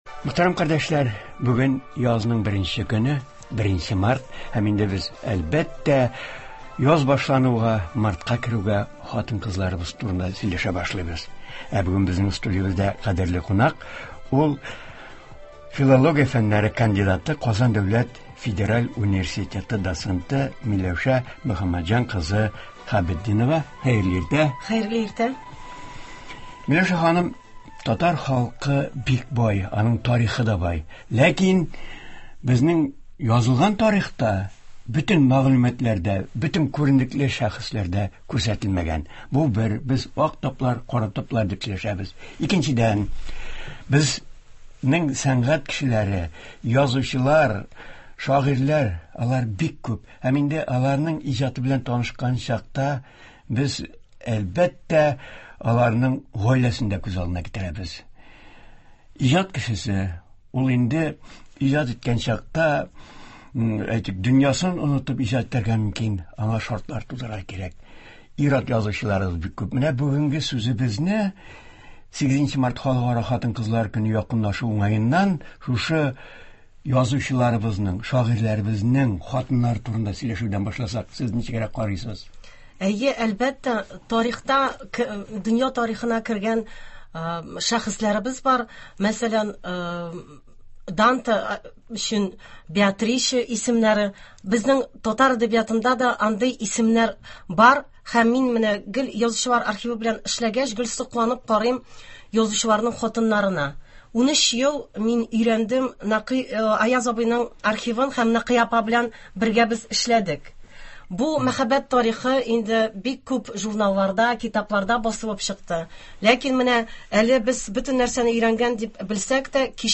Туры эфир (01.03.21) | Вести Татарстан